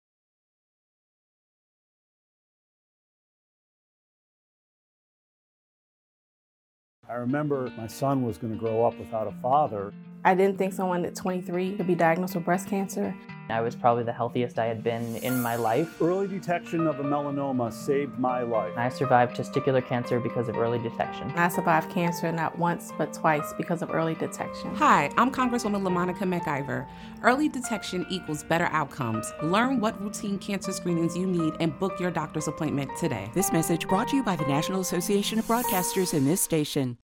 Rep. LaMonica McIver (NJ-10)